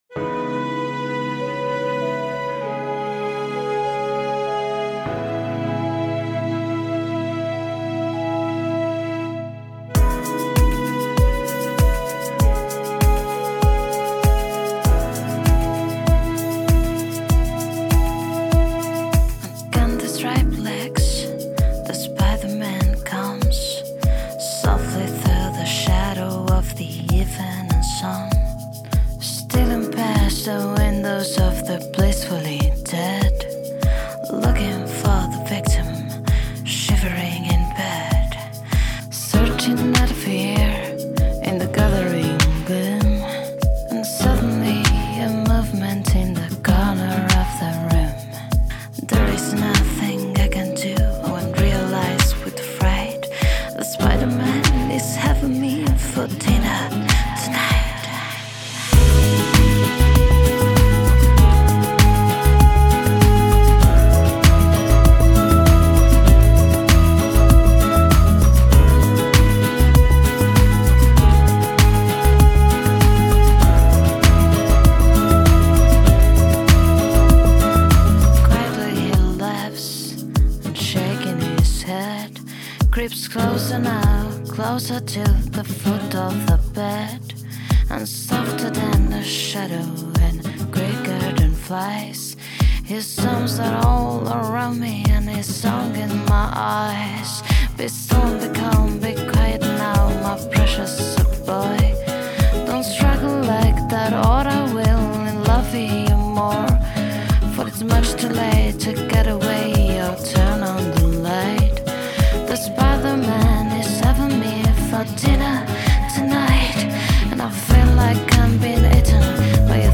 Downtempo, Lounge, Jazz